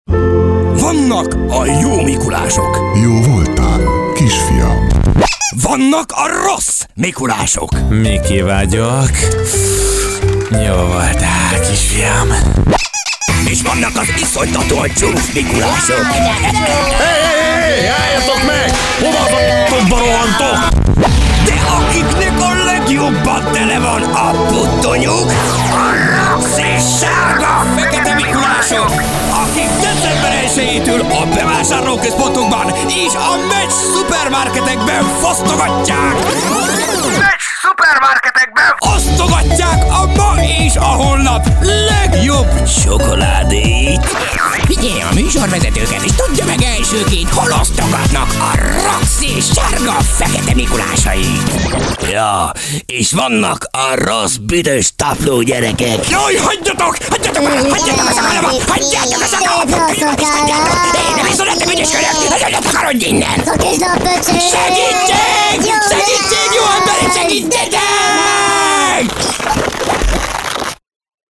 deep, cool, wry, energetic, announcer, sturdy, authoritative, corporate,
Sprechprobe: Werbung (Muttersprache):